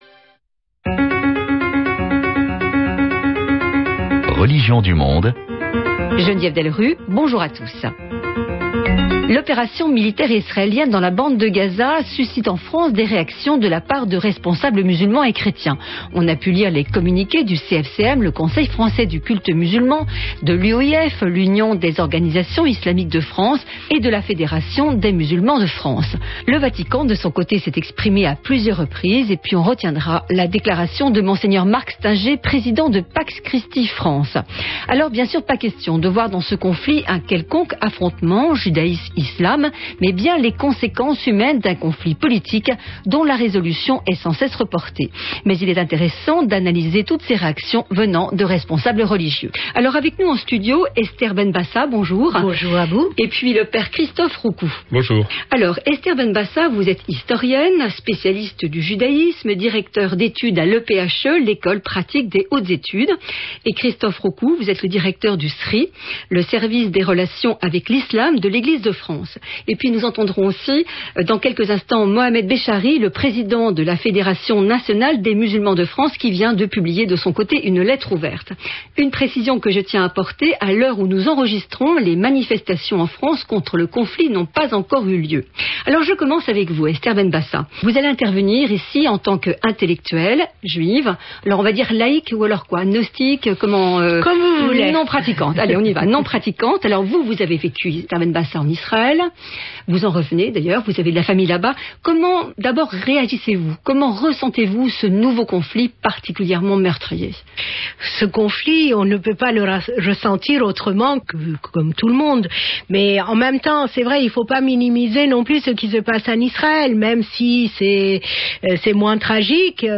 Réactions et analyses de responsables et intellectuels juifs, chrétiens et musulmans face à ce conflit.